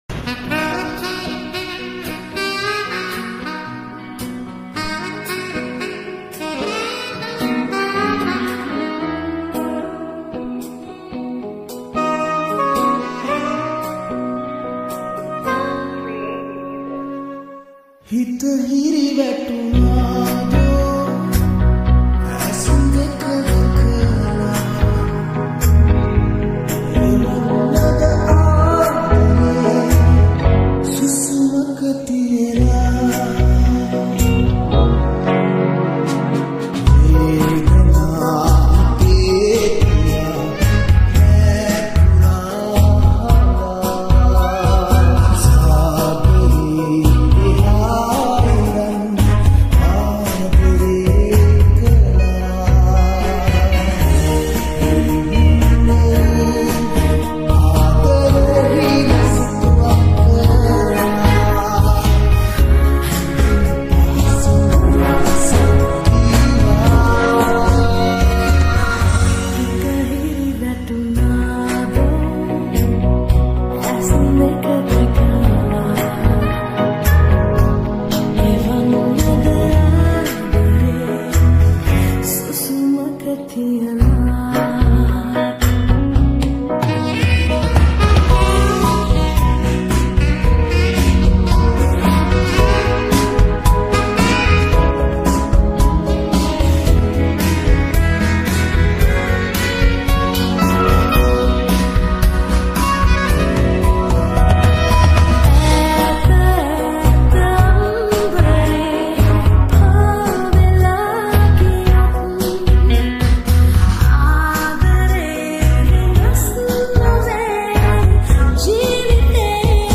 sri 8d music New Song